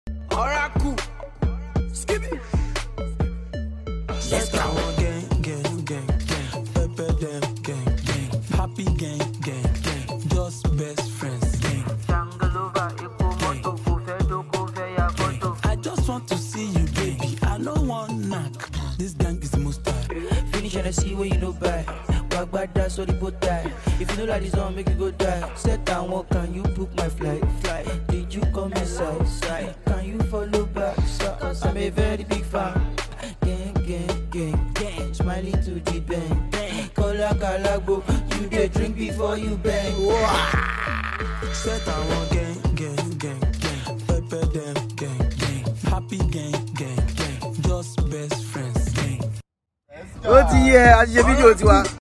exotic freestyle